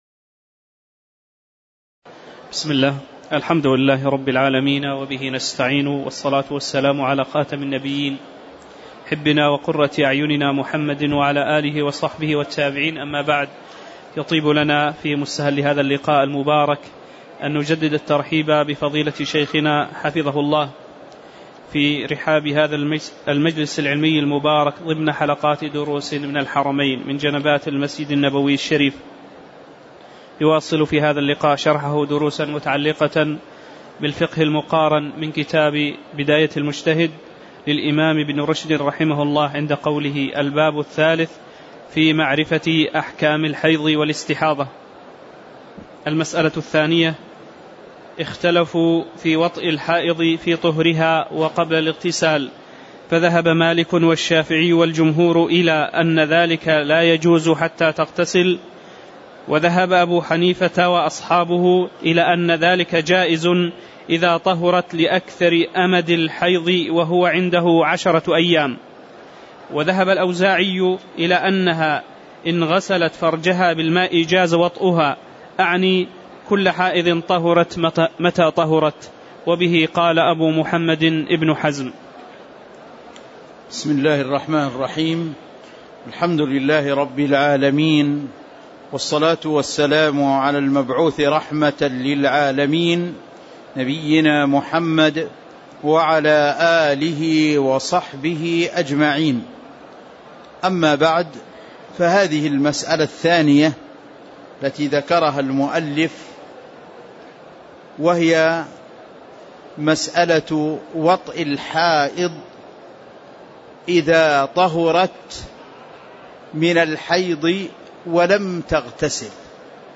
تاريخ النشر ٢٩ جمادى الأولى ١٤٤٠ هـ المكان: المسجد النبوي الشيخ